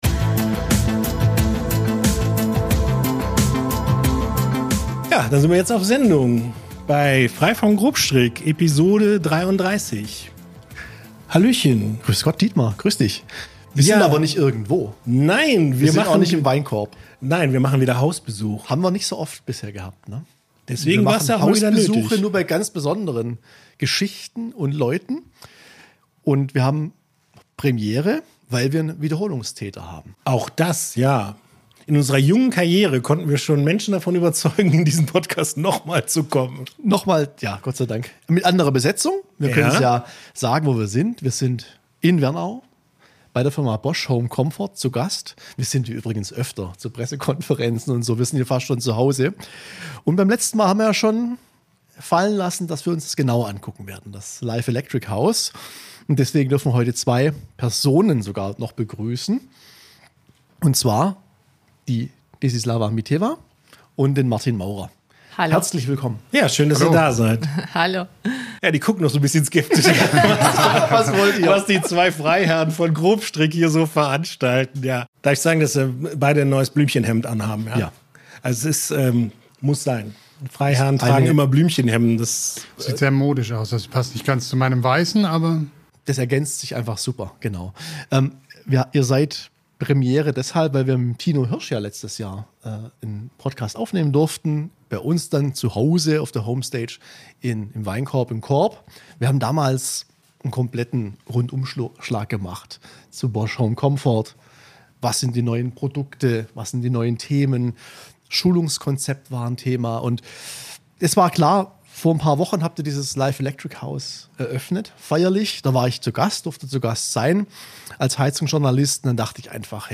Das Live Electric House bietet dafür den idealen Rahmen.